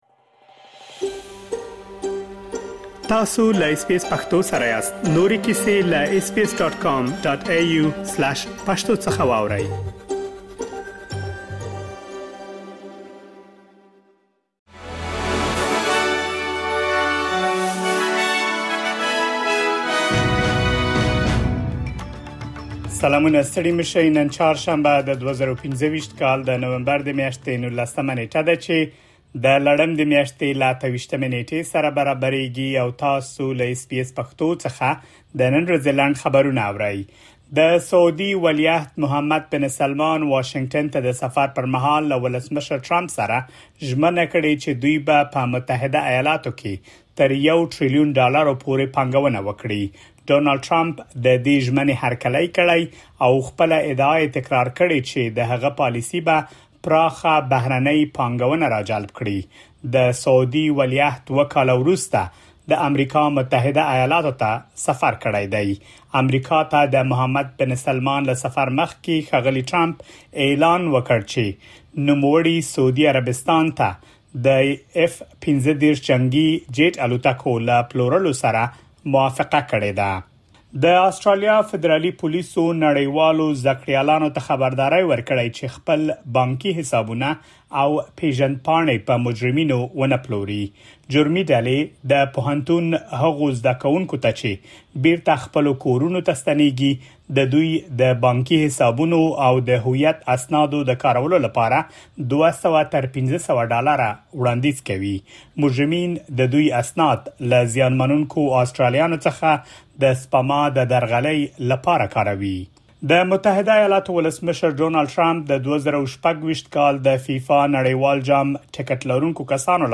د اس بي اس پښتو د نن ورځې لنډ خبرونه |۱۹ نومبر ۲۰۲۵
د اس بي اس پښتو د نن ورځې لنډ خبرونه دلته واورئ.